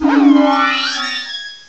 cry_not_togekiss.aif